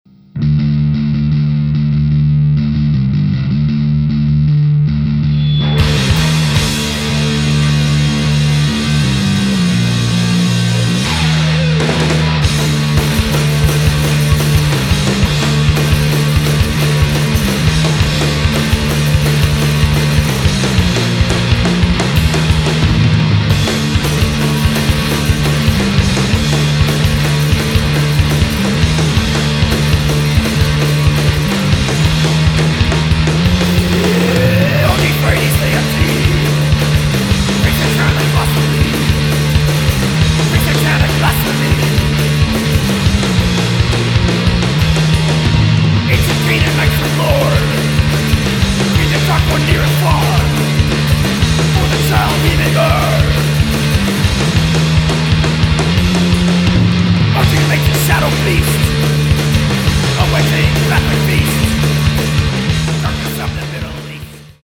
47 minutes of Rotten Metal Punk straight from Hades!!!